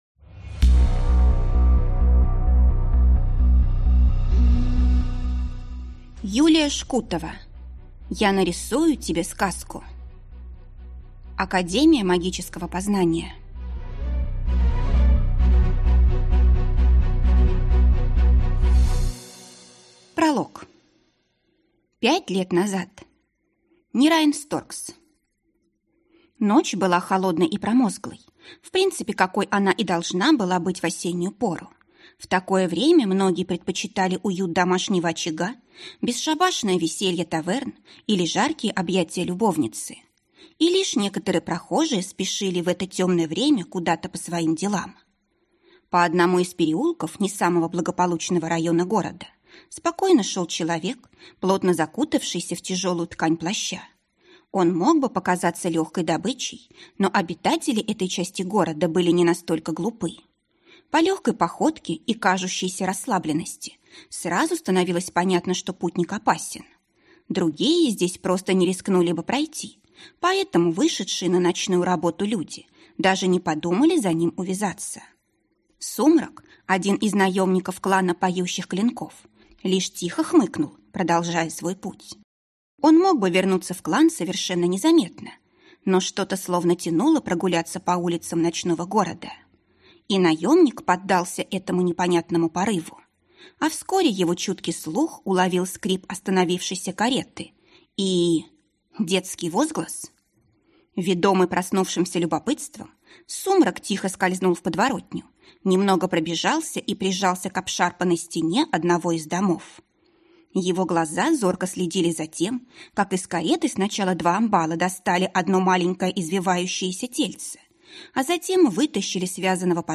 Аудиокнига Я нарисую тебе сказку | Библиотека аудиокниг